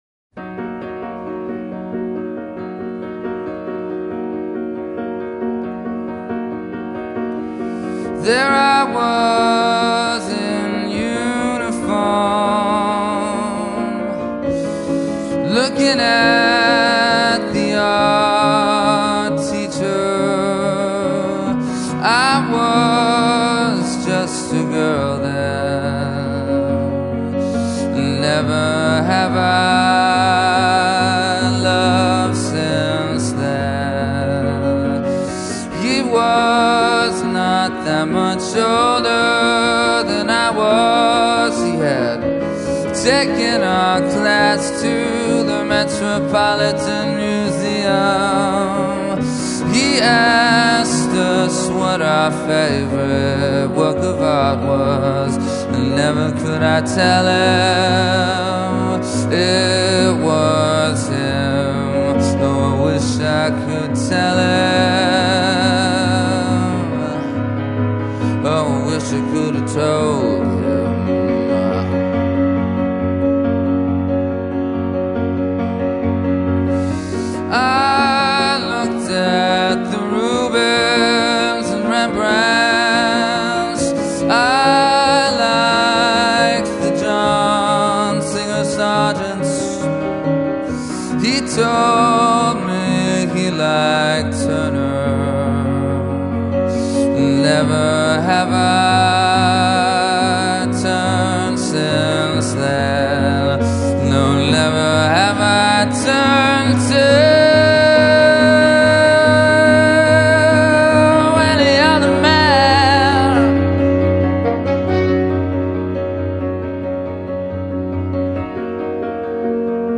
Posted in piano on January 27th, 2007 5 Comments »